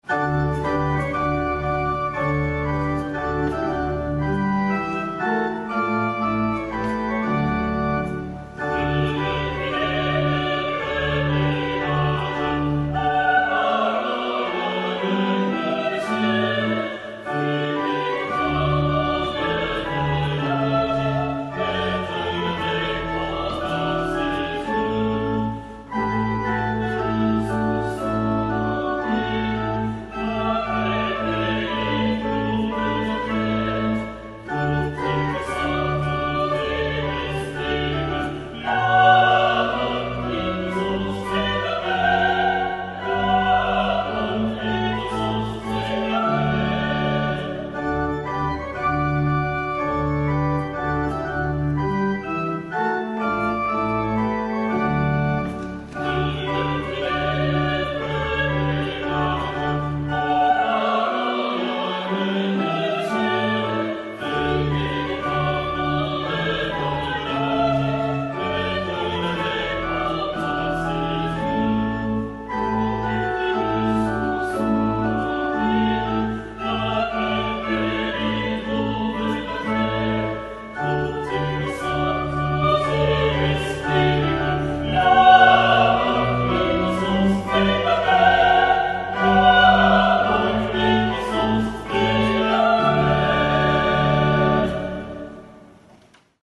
Orgue Gadault Saint-Acceul | Les amis de l'église St-Acceul à Ecouen
Orgue_Saint-acceul_01.mp3